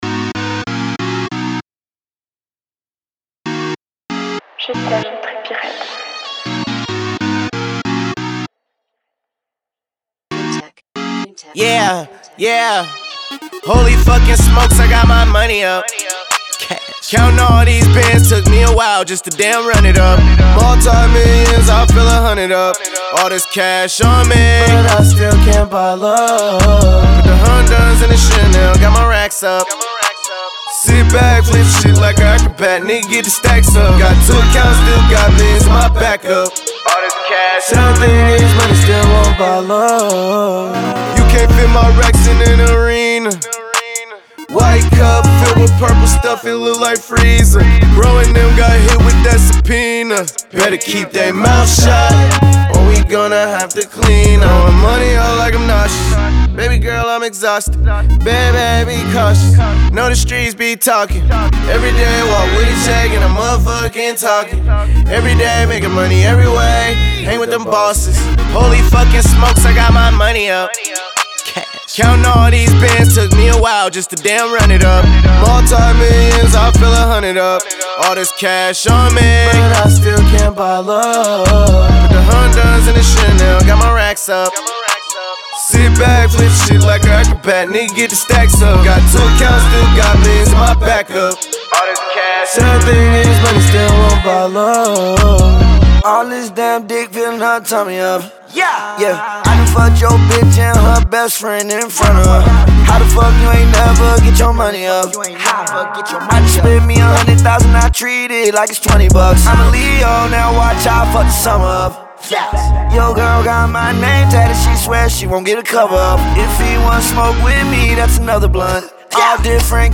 энергичная хип-хоп композиция